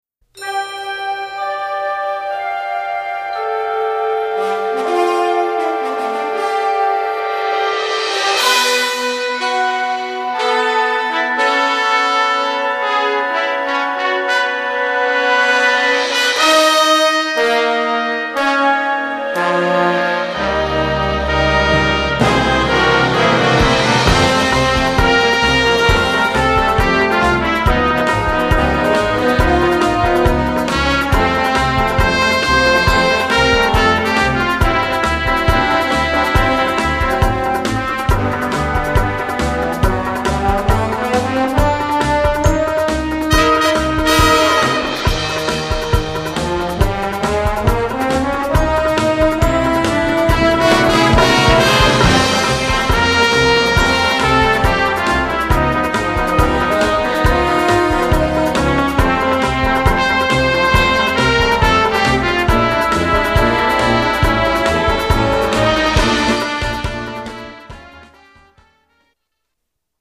23 x 30,5 cm Besetzung: Blasorchester Tonprobe